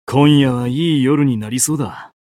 觉醒语音 今夜はいい夜になりそうだ 媒体文件:missionchara_voice_483.mp3